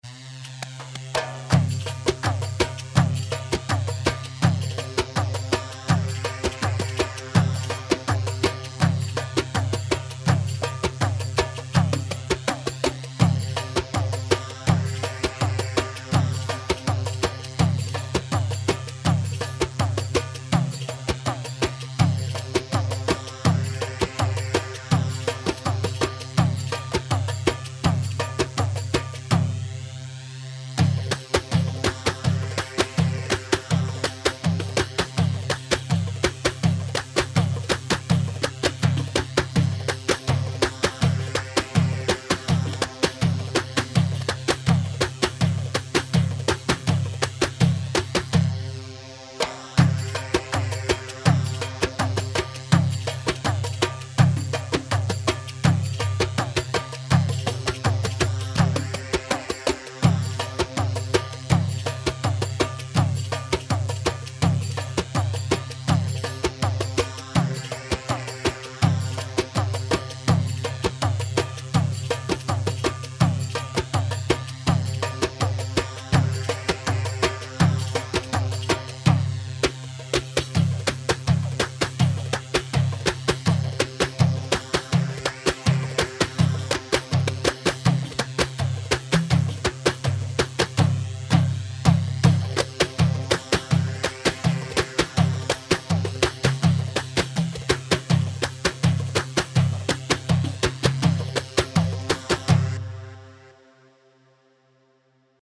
Janubi 4/4 82 جنوبي